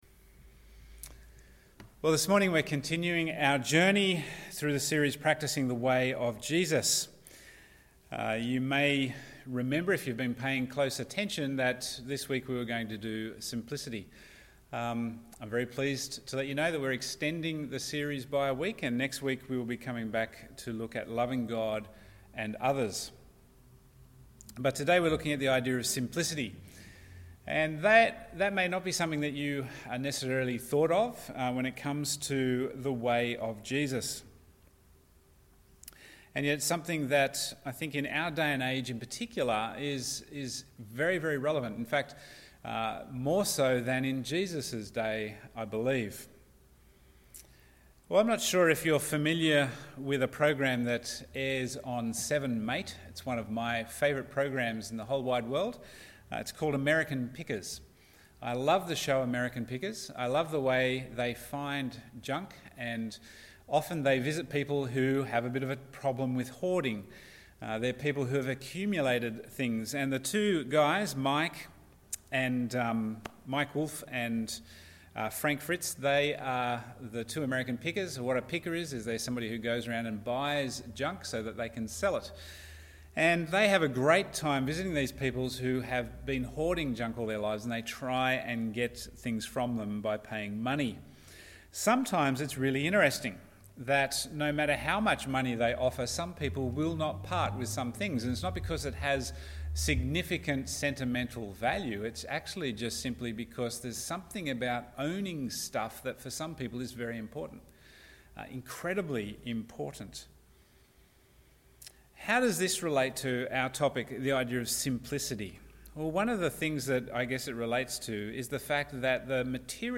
Bible Text: Matthew 6:19-21, Matthew 6: 25-34 | Preacher